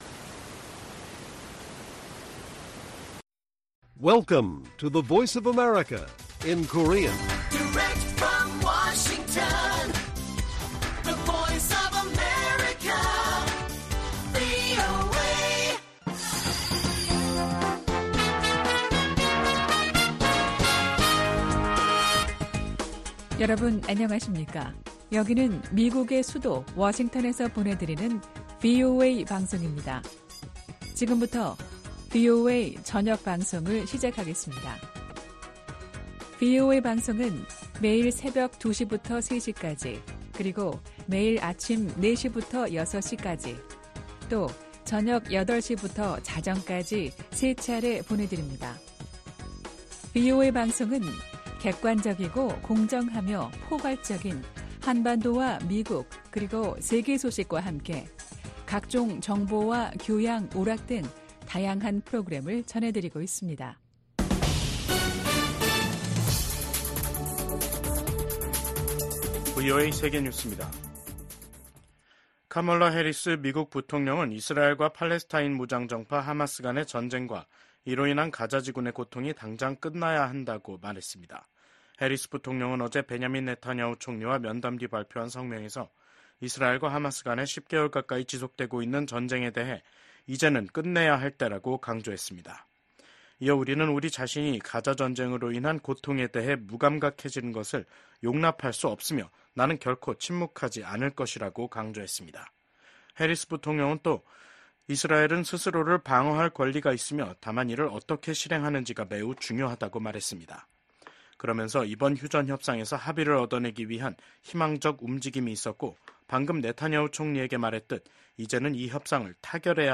VOA 한국어 간판 뉴스 프로그램 '뉴스 투데이', 2024년 7월 26일 1부 방송입니다.